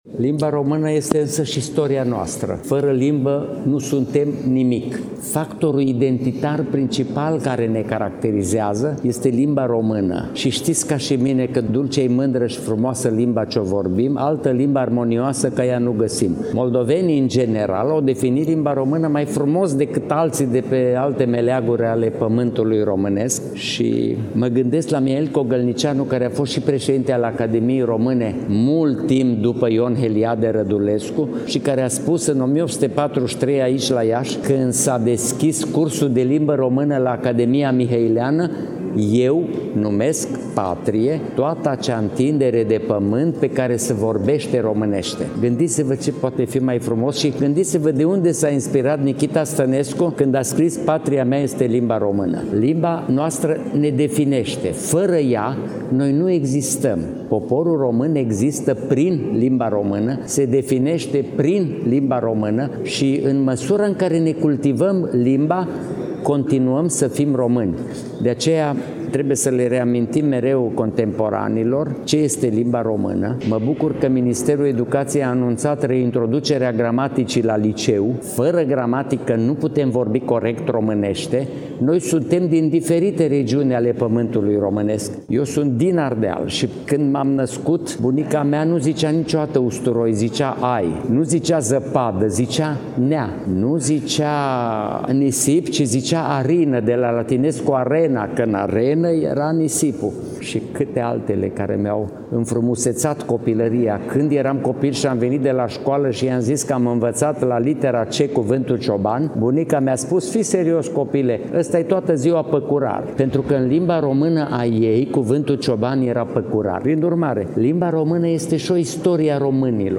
„Limba română ca istorie” prin vocea Academicianului Ioan-Aurel Pop
La Iași, în ziua de joi, 16 octombrie 2025, începând cu ora 17, în Sala Voievozilor din incinta Palatului Culturii s-a desfășurat conferința Limba română ca istorie. Conferința a fost suținută de către academicianul Ioan-Aurel Pop, președinte al Academiei Române.
2_Academician-Ioan-Aurel-Pop-Limba-Romana-3-25.mp3